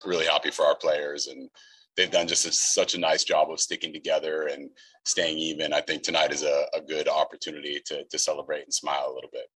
San Francisco Giants manager, Gabe Kapler, said he is happy with the team’s abilities. Kapler believes they have much reason to celebrate their victory.